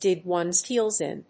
díg one's héels ín